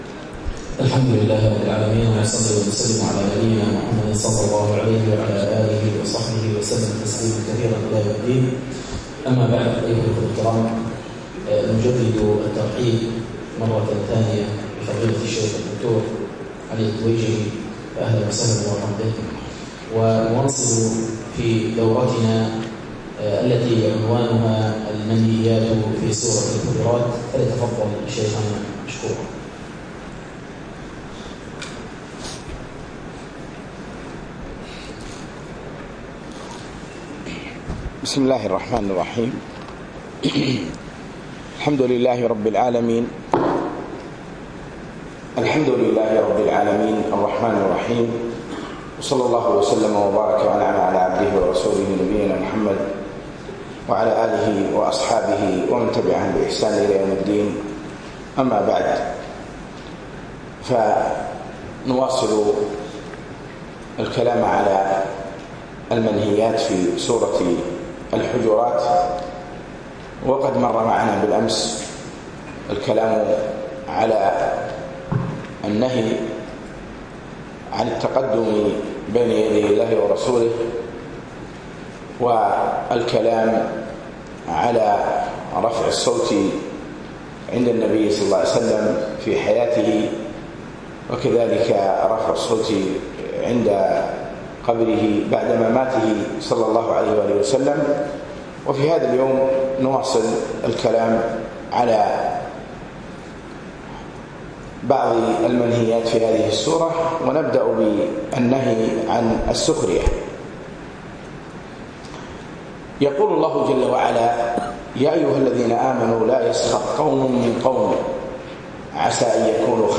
الدرس الثاني